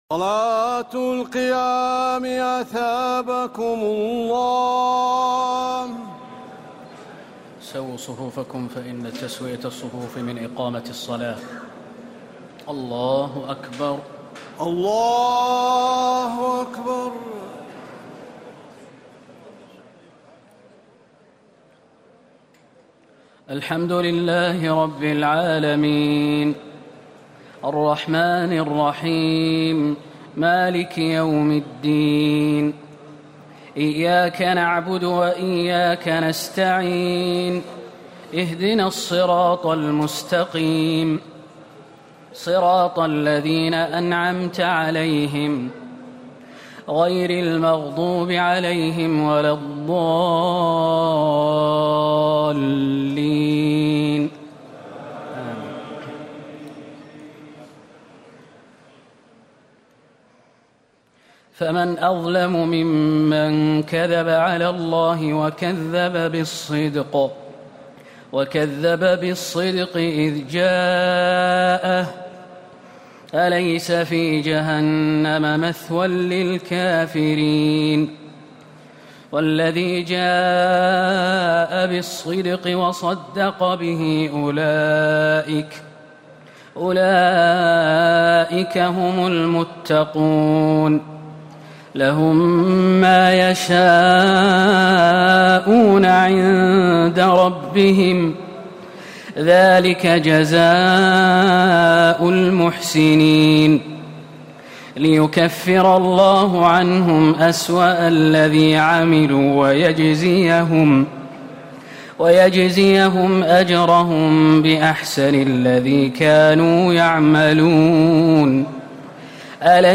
تراويح ليلة 23 رمضان 1439هـ من سور الزمر (32-75) و غافر (1-46) Taraweeh 23 st night Ramadan 1439H from Surah Az-Zumar and Ghaafir > تراويح الحرم النبوي عام 1439 🕌 > التراويح - تلاوات الحرمين